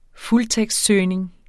Udtale [ ˈfultεgsd- ]